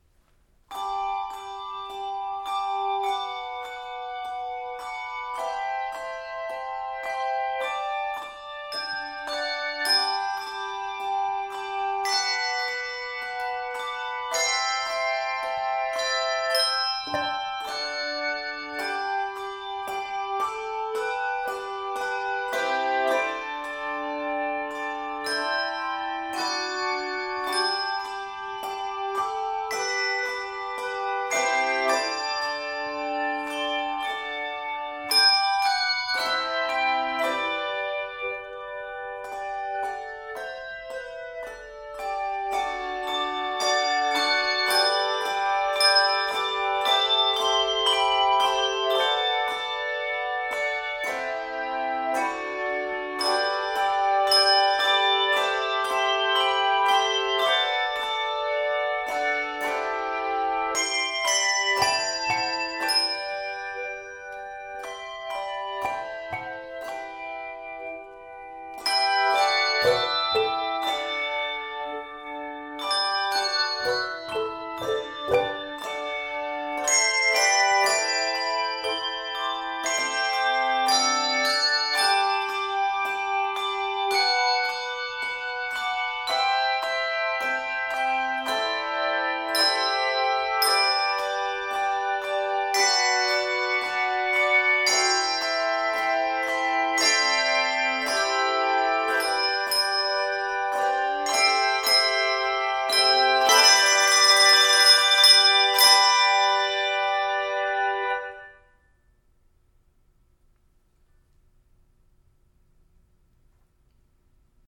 Written for 2 or 3 octaves of handbells or handchimes